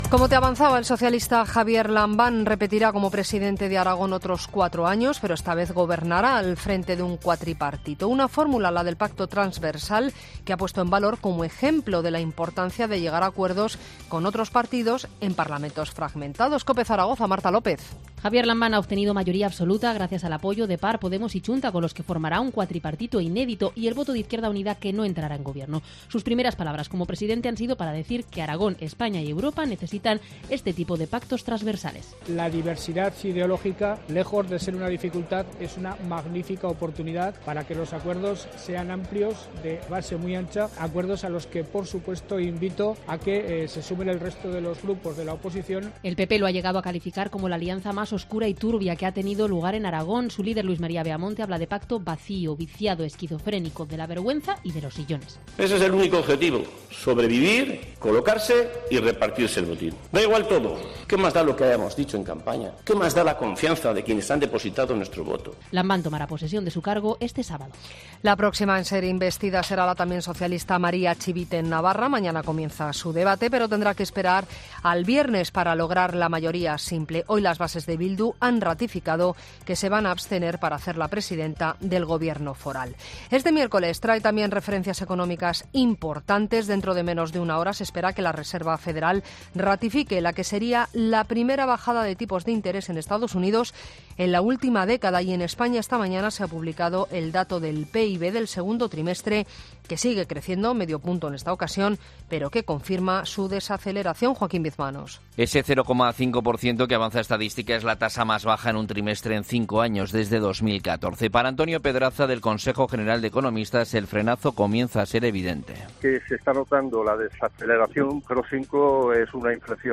Boletín de noticias de COPE del 31 de julio de 2019 a las 19.00 horas